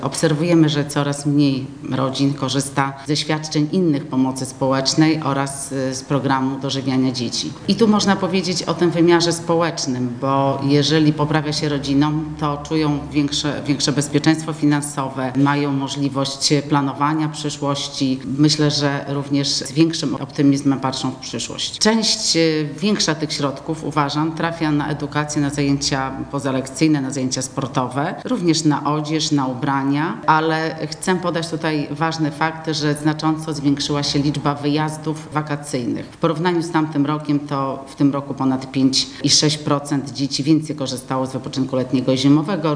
– Efektem programu jest stabilizacja finansowa rodzin – mówi senator Kopiczko.